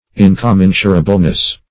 -- In`com*men"su*ra*ble*ness, n. -- In`com*men"su*ra*bly,